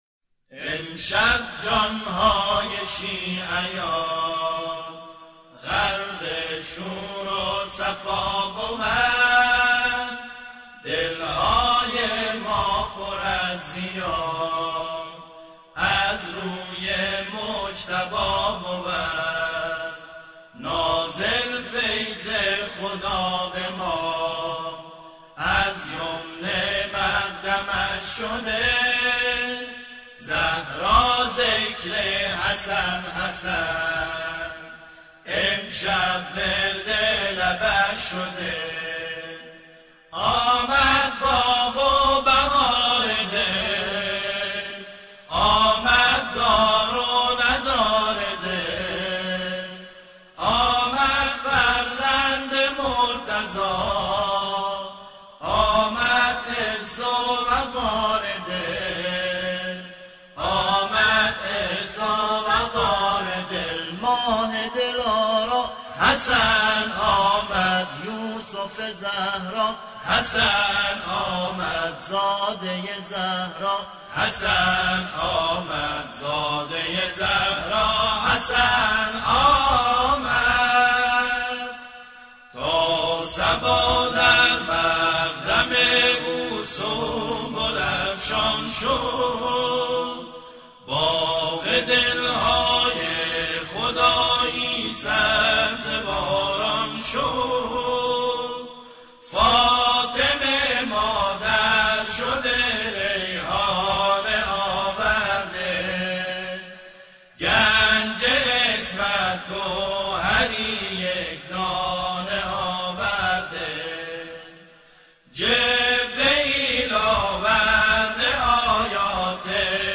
گروه هم‌خوانی